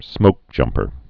(smōkjŭmpər)